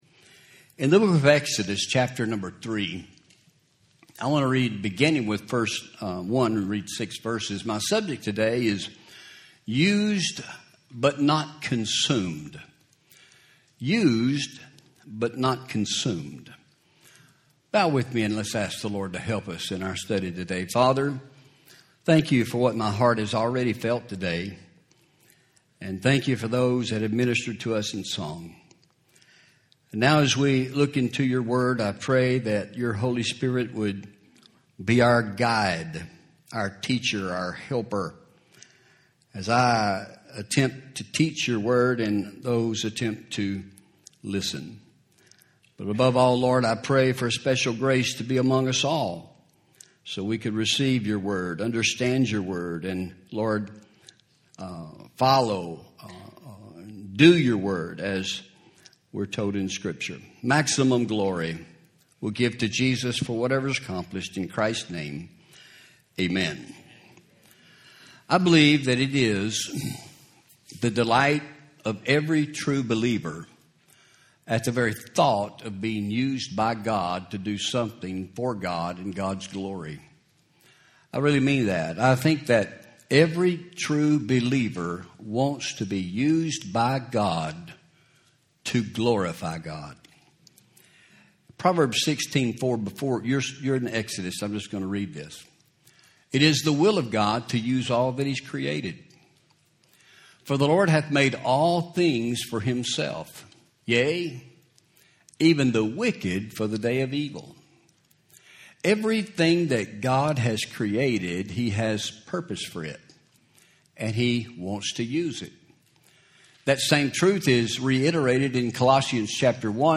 Home › Sermons › Used But Not Consumed